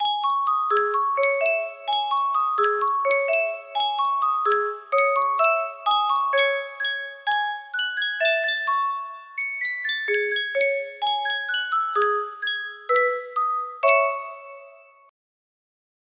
Yunsheng Carillon - Bella Ciao 3770 music box melody
Full range 60